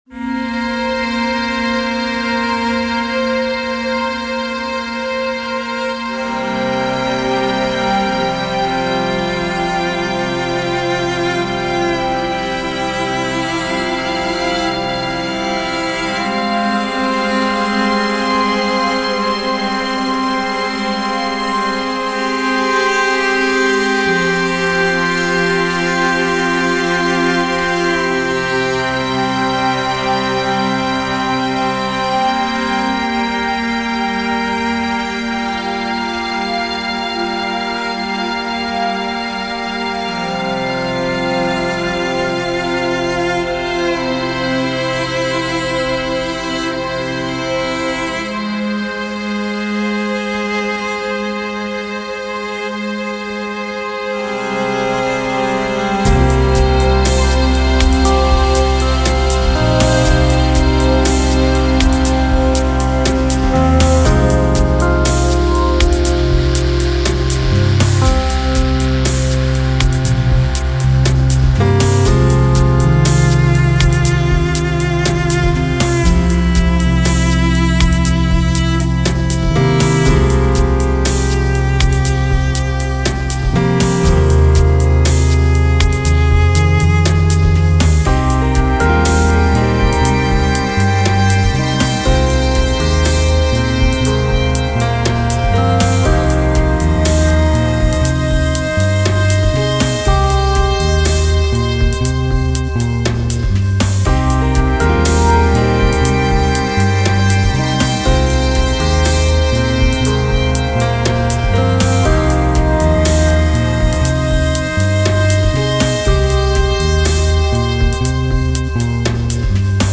Uncertain (Electronic Chamber Music)